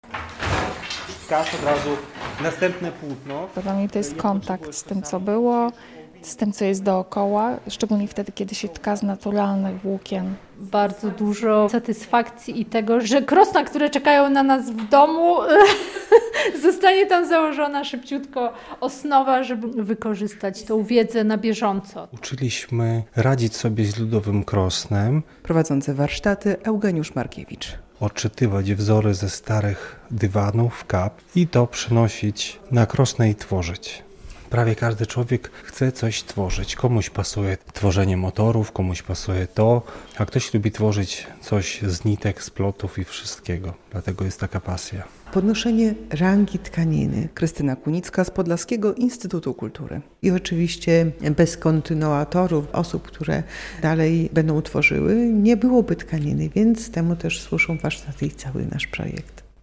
Warsztaty tkackie w Niemczynie - relacja